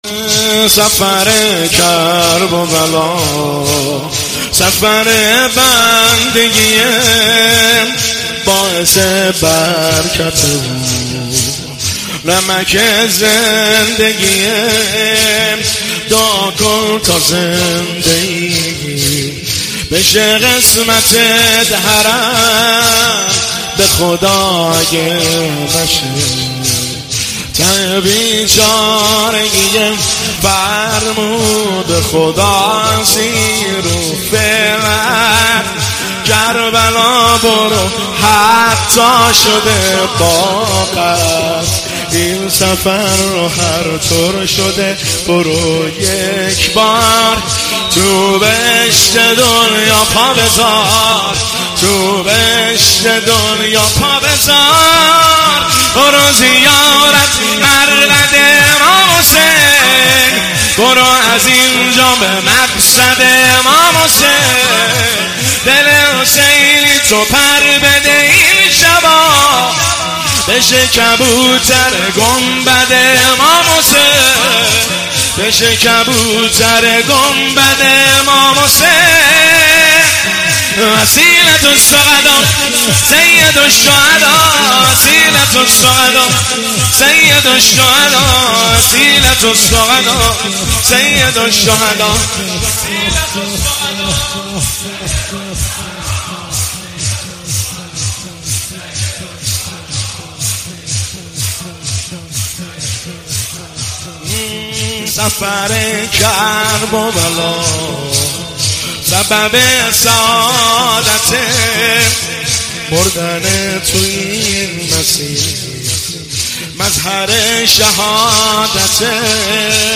هیئت بین الحرمین طهران